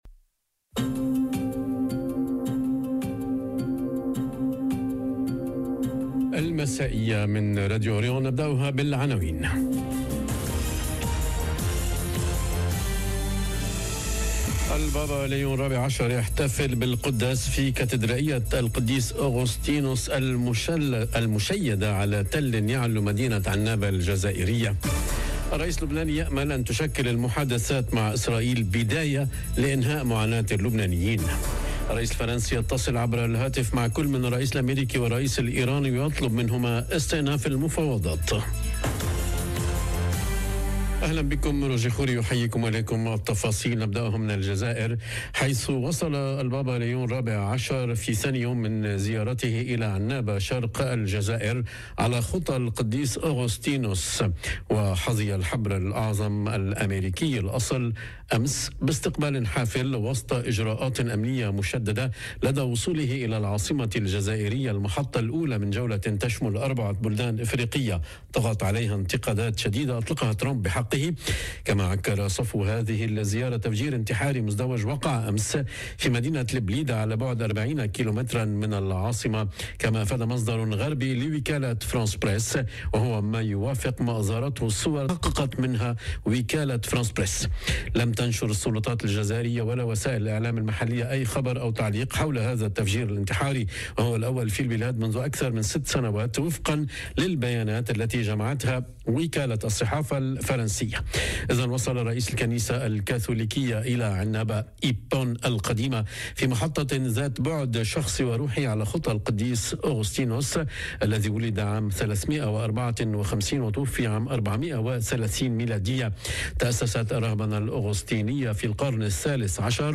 نشرة المساء.. ماكرون يطالب أميركا وإيران باستئناف التفاوض - Radio ORIENT، إذاعة الشرق من باريس